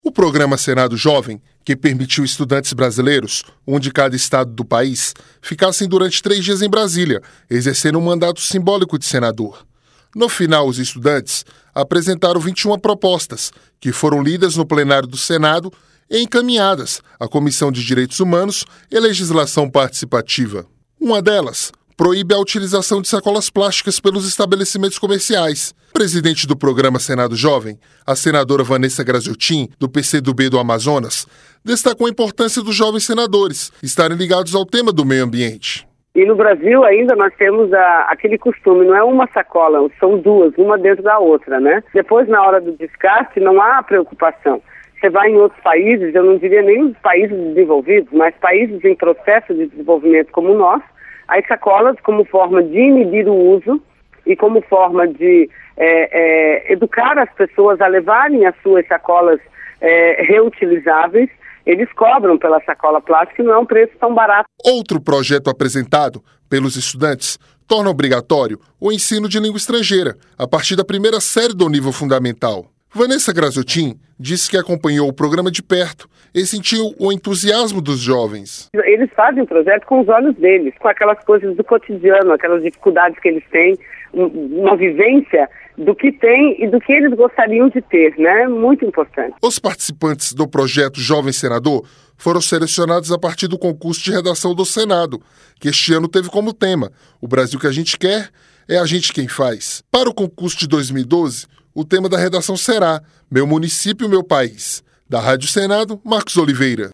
Presidente do programa Senado Jovem, a senadora Vanessa Grazziotin, do PC do B do Amazonas, destacou a importância dos jovens estarem ligados ao tema do meio ambiente.